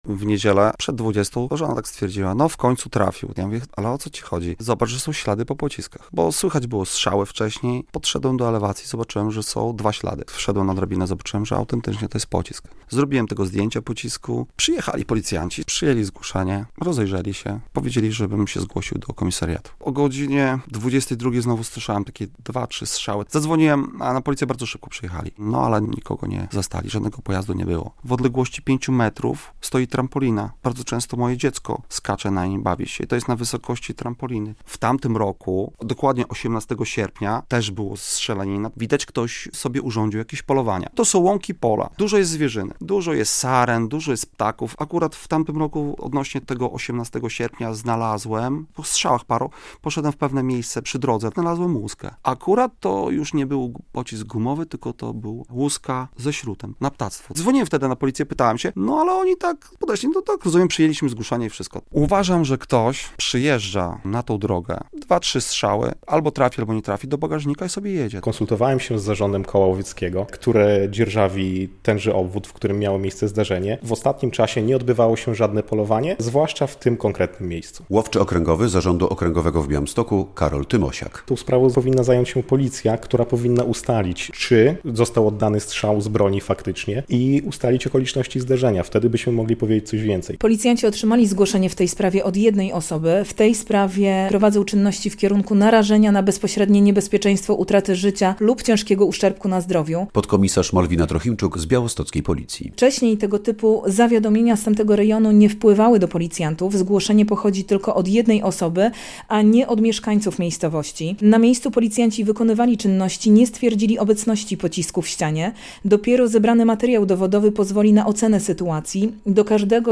Policja ustala, czy ktoś mógł ostrzelać dom w Fastach koło Białegostoku - relacja